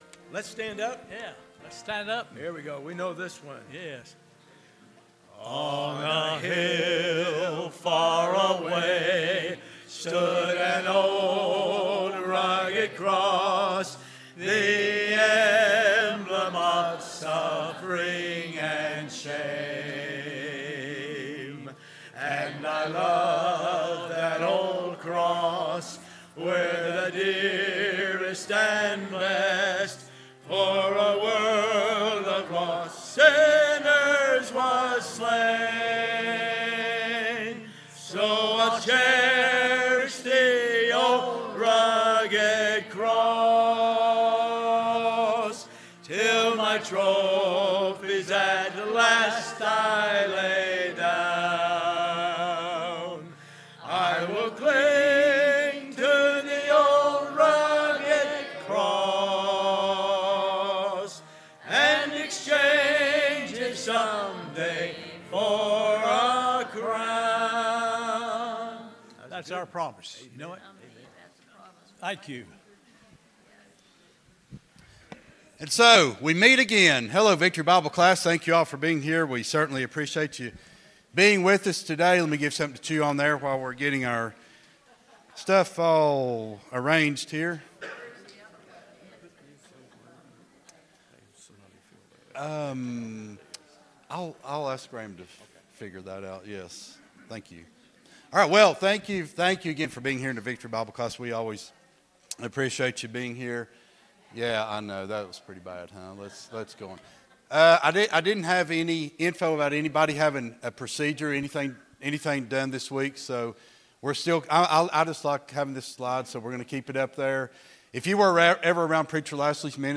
Sunday School Lesson